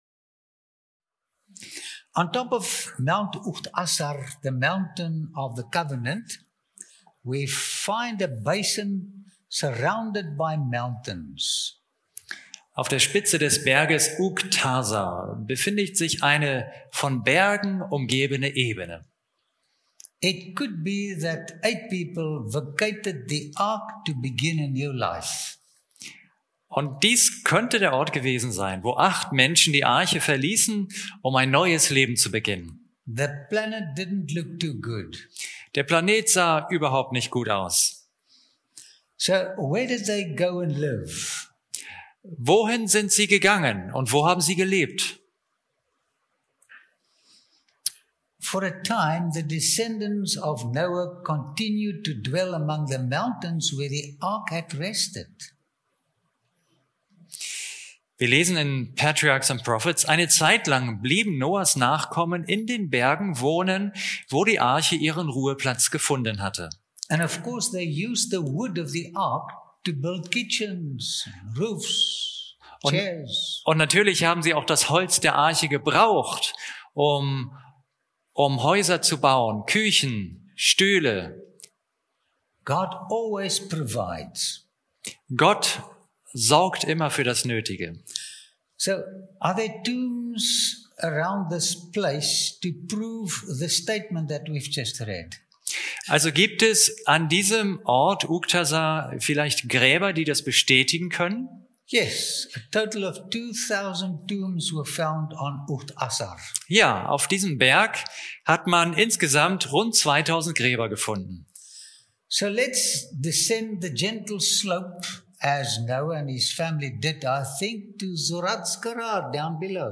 In den reich bebilderten Vorträgen erhält der Zuschauer nicht nur faszinierende Einblicke in vergangene Kulturen, sondern begegnet Menschen, die vor Tausenden von Jahren ganz ähnliche Freuden und Sorgen hatten wie wir – und deren ermutigende Erfahrungen auch heute noch erlebbar sind, wenn wir von ihnen lernen und den verlorenen Schatz des Vertrauens in Gott wiederfinden.